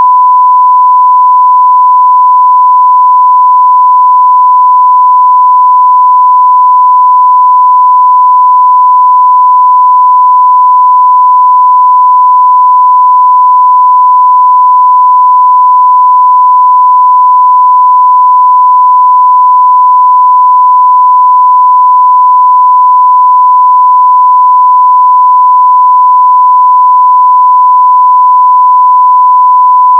您是否具有音调/文件(1KHz 正弦波形)来测试器件？
下面是一个满量程1KHz。
1KHz.wav